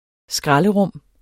Udtale [ ˈsgʁɑlə- ]